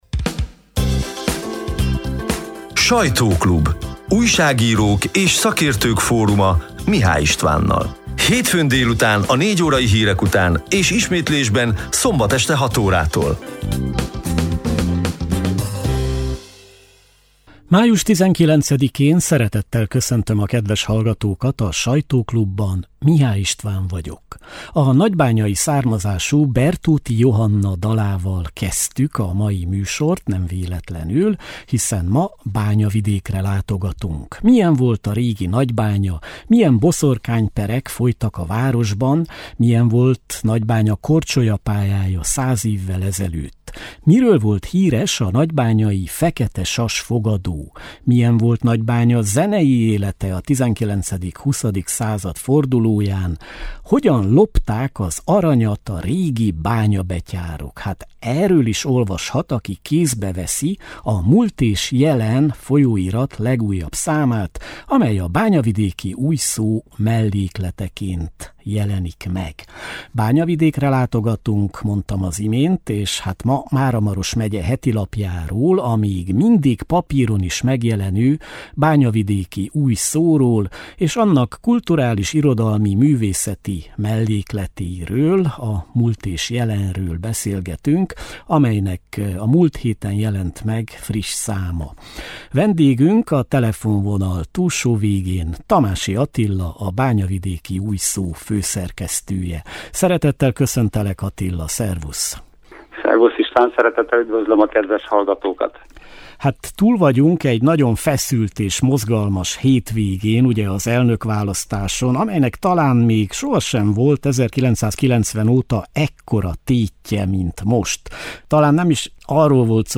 A lejátszóra kattintva a május 19-i, hétfő délutáni 55 perces élő műsor kissé rövidített változatát hallgathatják meg.